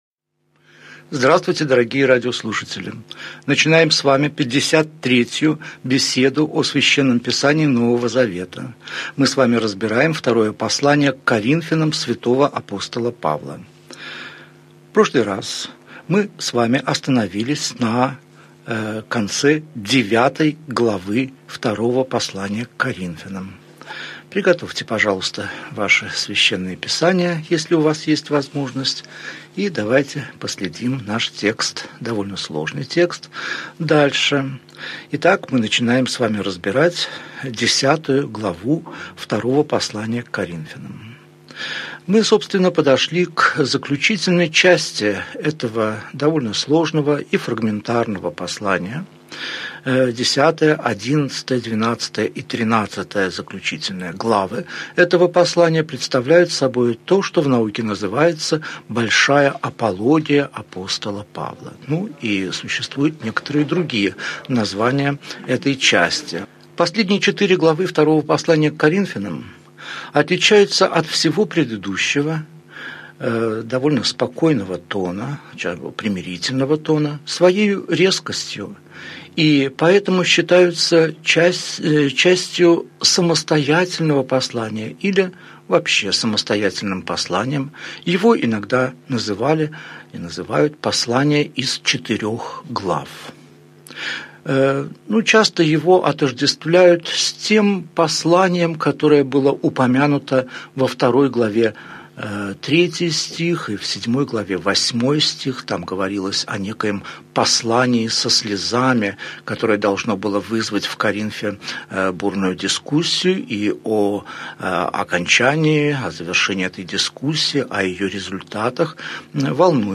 Аудиокнига Беседа 53. Второе послание к Коринфянам. Глава 10, стихи 1 – 6 | Библиотека аудиокниг